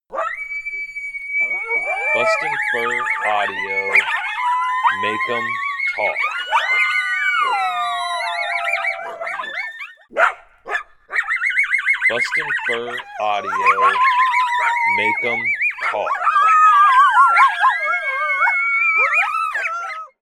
Lots of howling and chattering in this Group Howl that is for sure to get them talking.